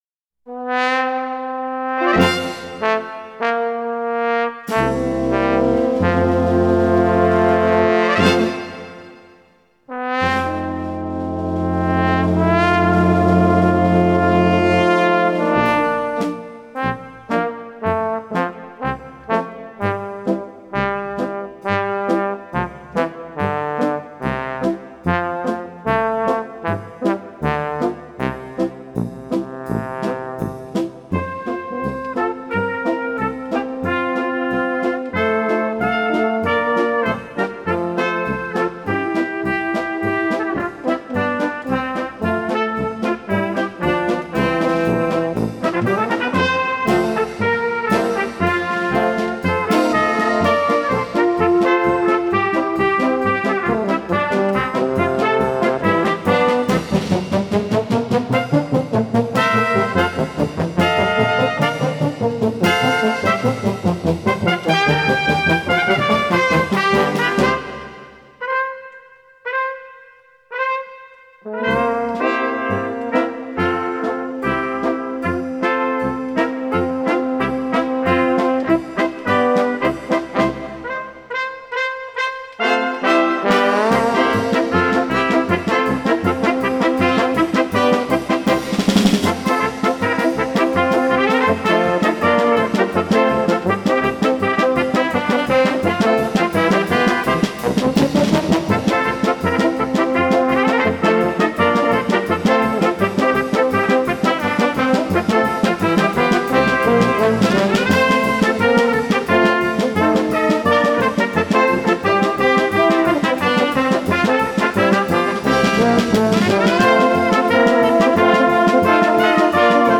Духовые оркестры